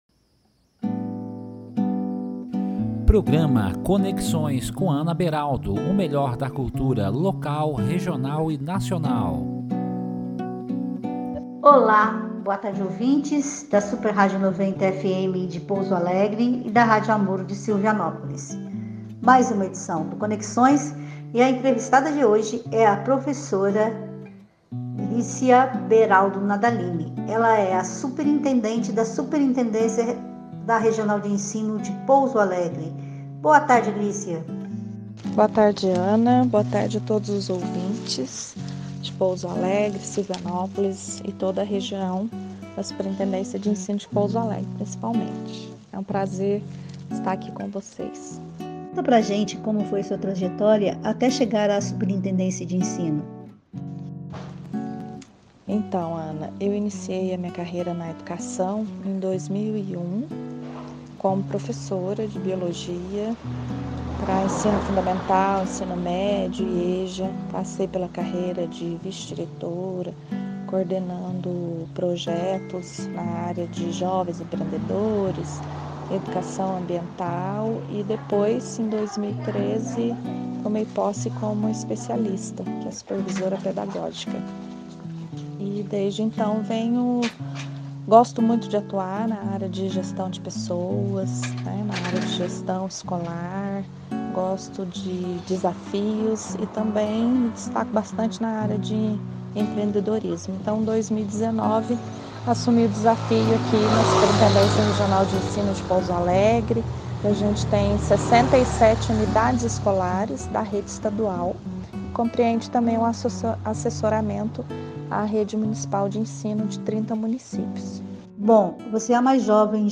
Com ênfase para entrevistas na área cultural, o Programa vai ao ar aos domingos, às 13h30.